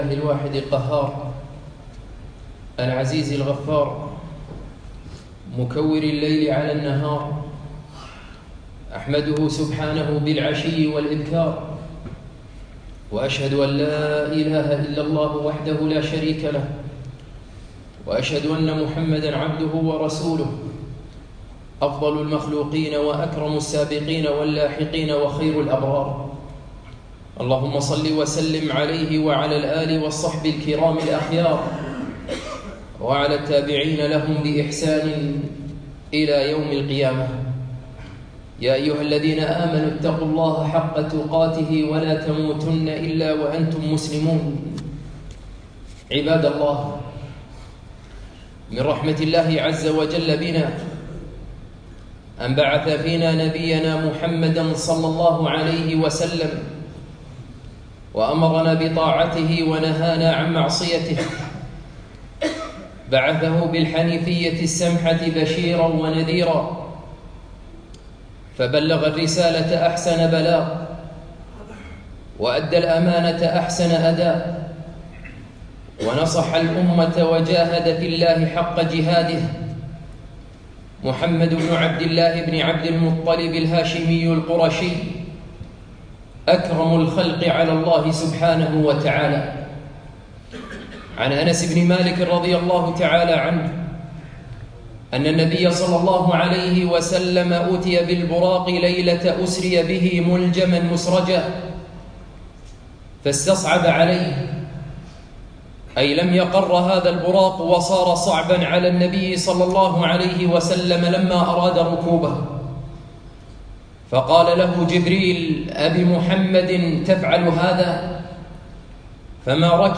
خطبة هل أحببناه ؟